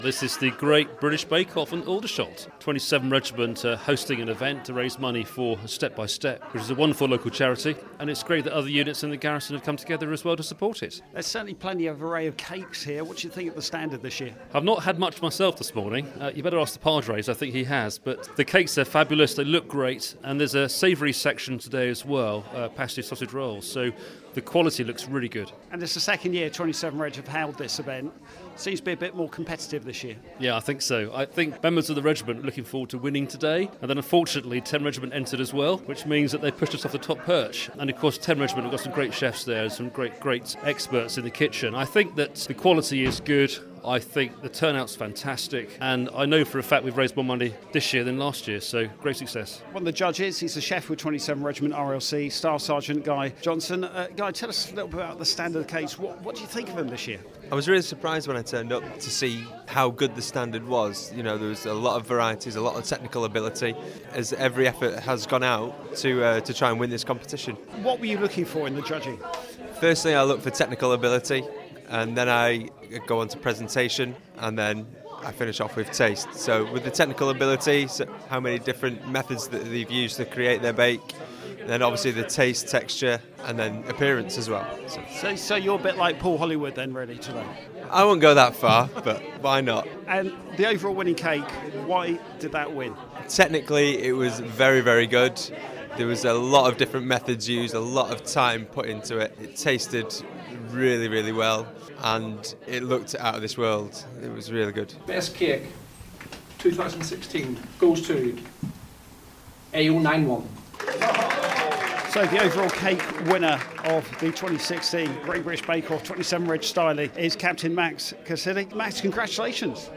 27 Regiment RLC Welfare team have hosted their second Great British Bake Off at the Connaught Community Centre, Aldershot.